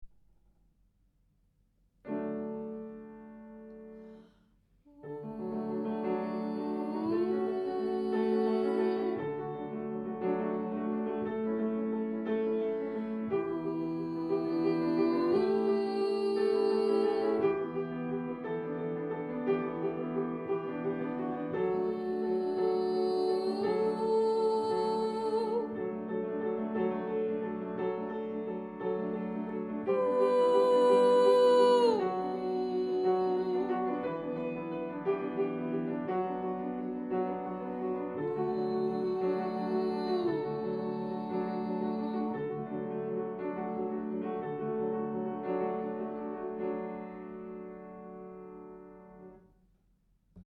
Gliss Dmaj
B-Gliss-High.mp3